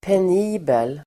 Uttal: [pen'i:bel]